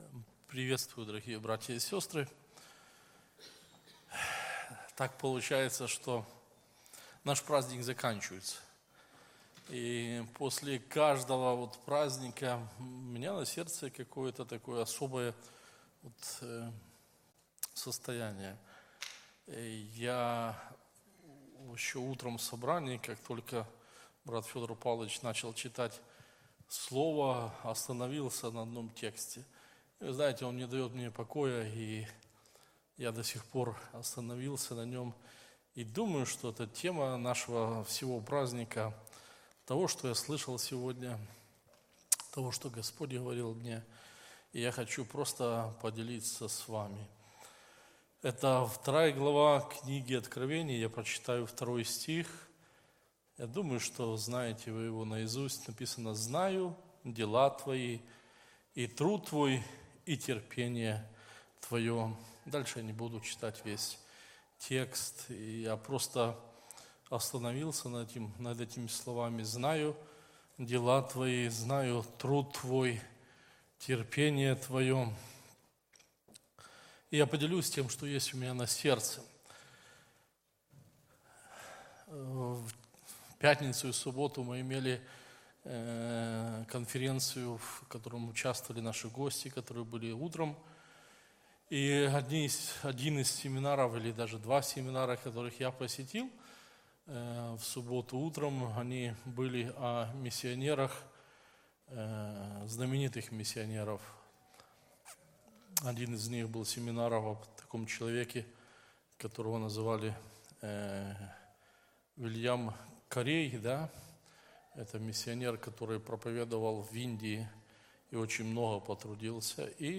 Church4u - Проповеди